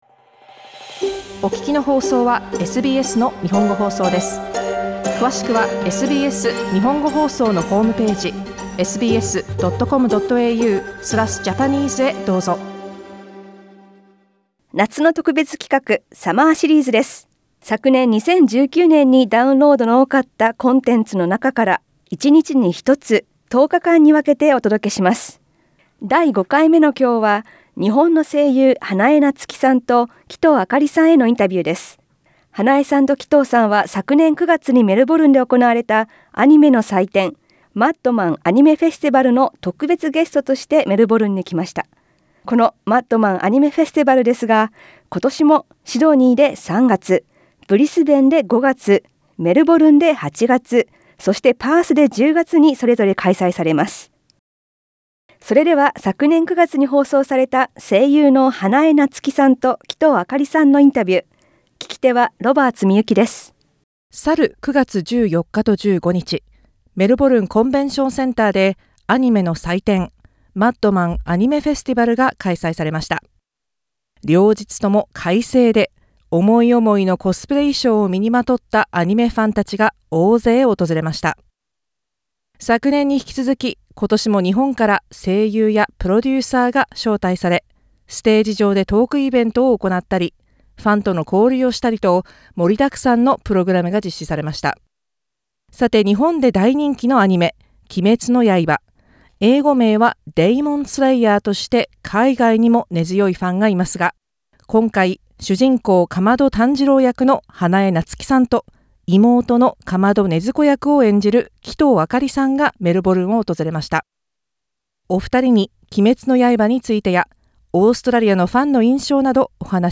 Voice actors of Demon Slayer Natsuki Hanae (L) and Akari Kito (R) Source: Supplied
第5回は昨年9月に放送された、アニメ「鬼滅の刃」の声優、花江夏樹さんと鬼頭明里さんへのインタビューです。